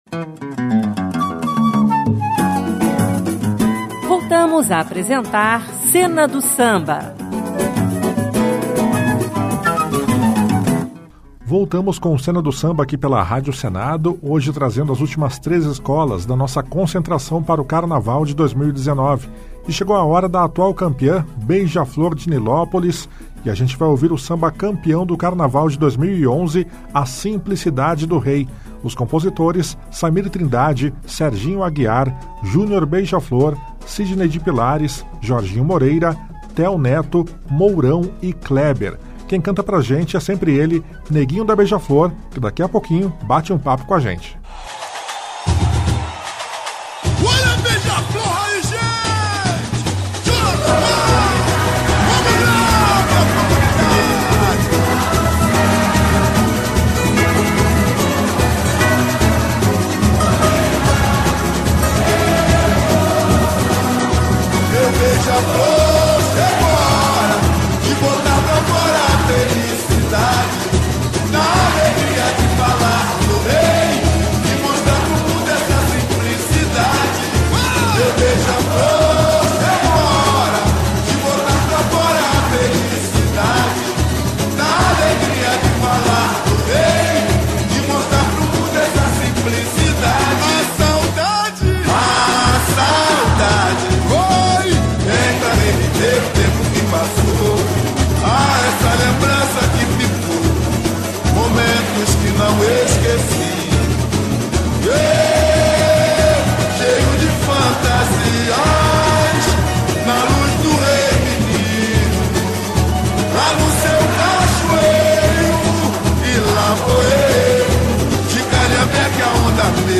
O Cena do Samba segue em ritmo de carnaval. Neste programa seguimos a série de entrevistas com intérpretes das escolas de samba do Grupo Especial do Rio de Janeiro.